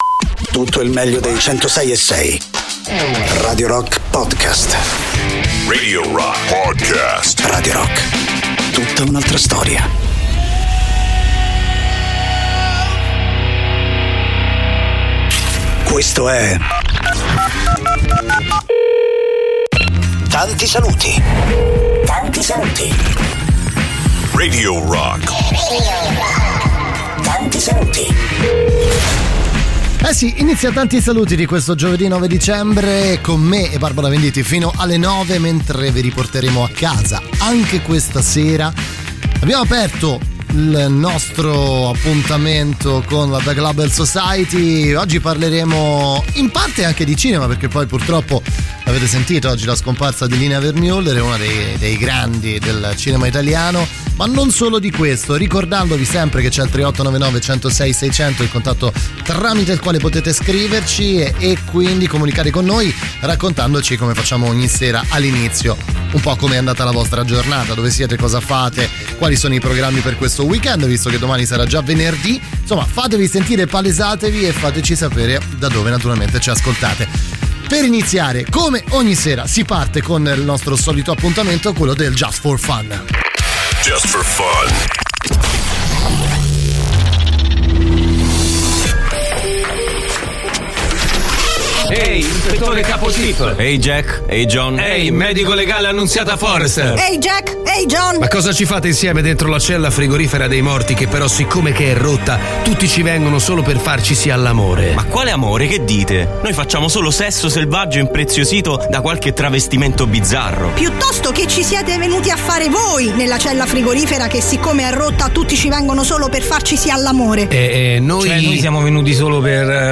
in diretta dal lunedì al venerdì, dalle 19 alle 21,